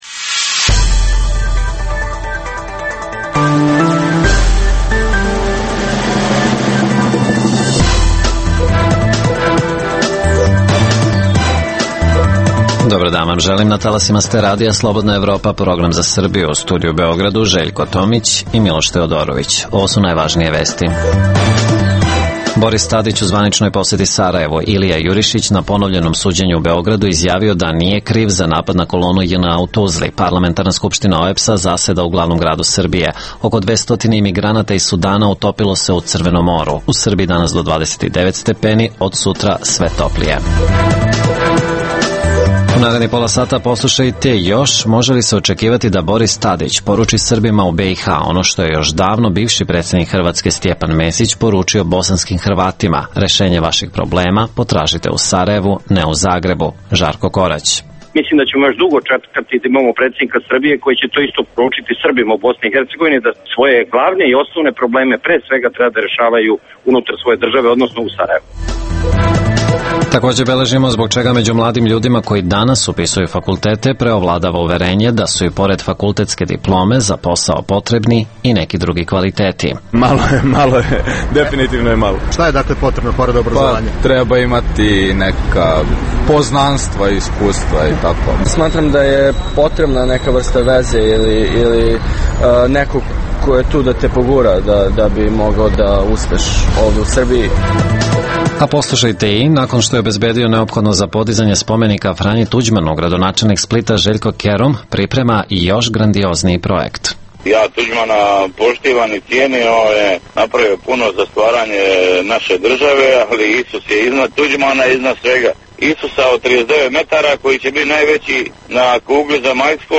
- Takođe, za RSE govore beogradski brucoši koji upisuju fakultete, ali i poručuju da diploma nije najvažnija.